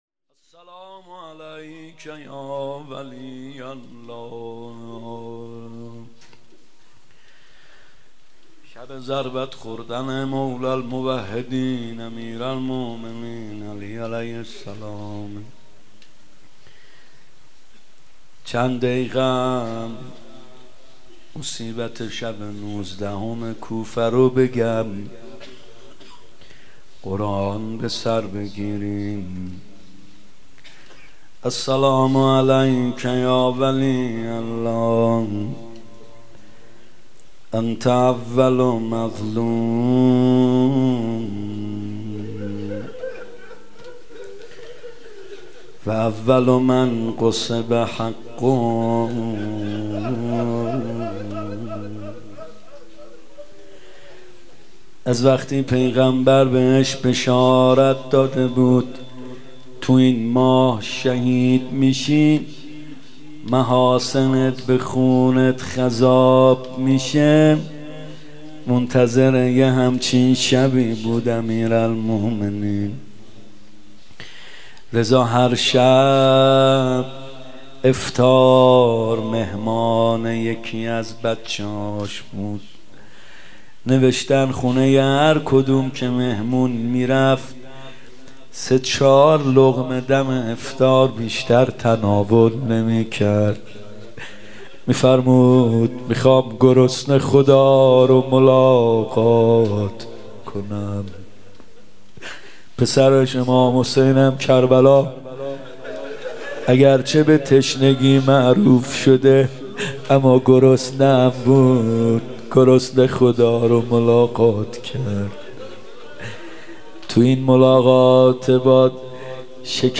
ماه مبارک رمضان 96 روضه